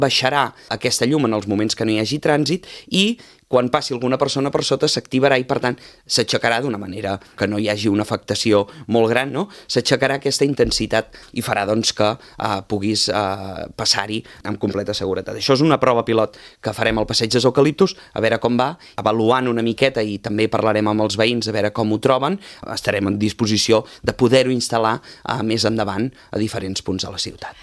Així ho ha anunciat el tinent d’alcaldia de Serveis públics, Josep Grima, a l’ENTREVISTA POLÍTICA de Ràdio Calella TV, l’espai setmanal amb els portaveus polítics municipals.